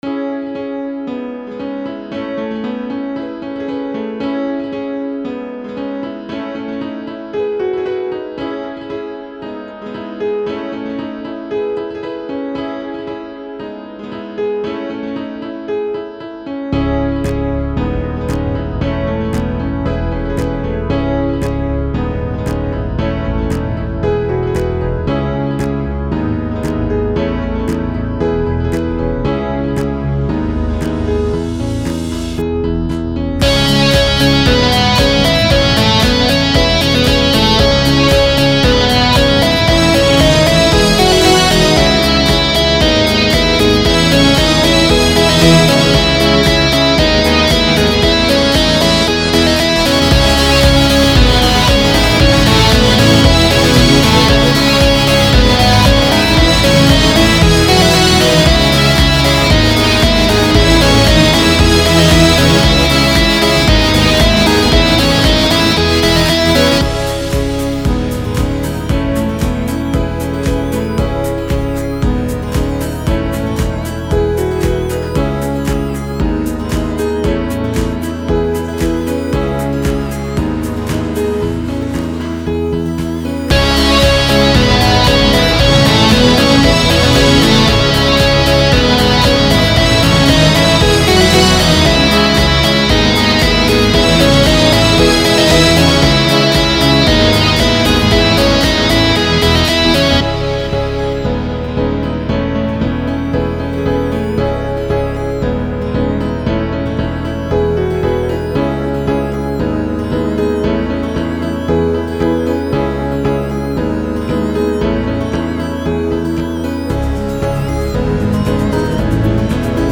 קטע אינסטרומנטלי חדש!!!
עריכה: גרסה שניה עם מיקס שונה…
נ.ב. לא עבר מיקס עד הסוף ולא מאסטרינג.